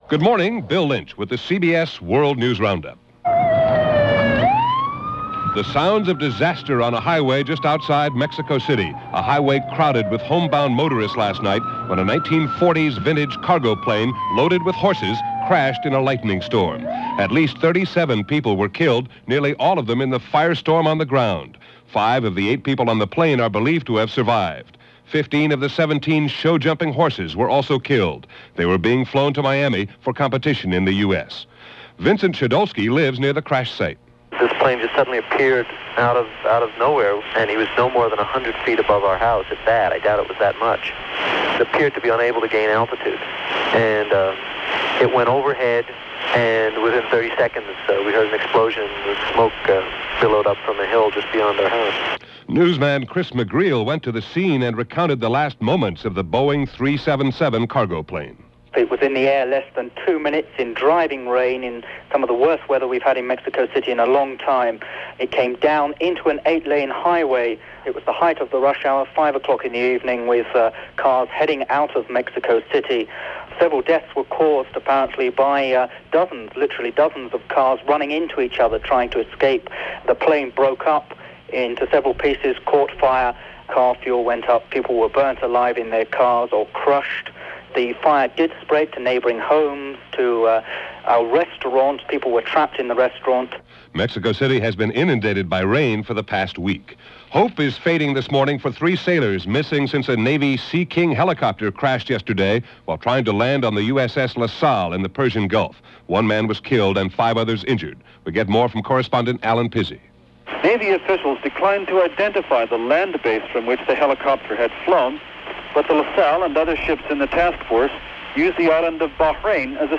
And that’s a very small slice of what went on this last day of July, 30 years ago, as presented by The CBS World News Roundup.